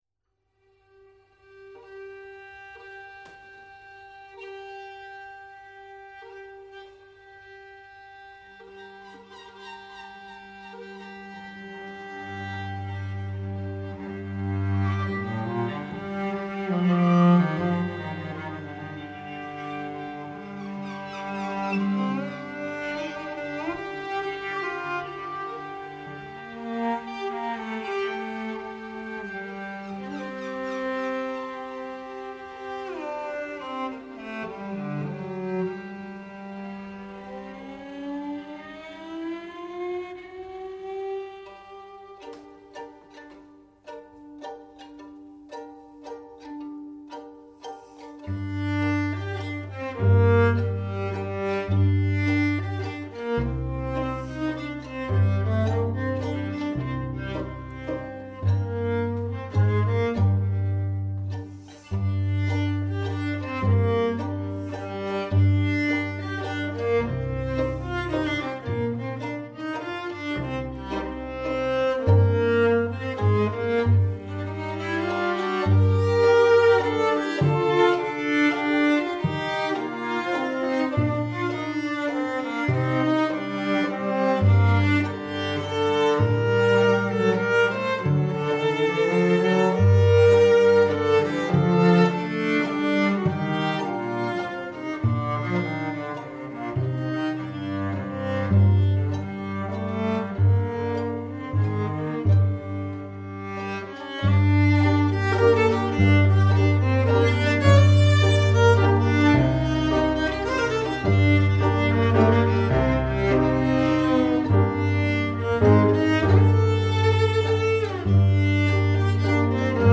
Valse
Mazurka